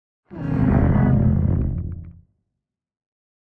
Minecraft Version Minecraft Version 1.21.4 Latest Release | Latest Snapshot 1.21.4 / assets / minecraft / sounds / mob / warden / ambient_3.ogg Compare With Compare With Latest Release | Latest Snapshot
ambient_3.ogg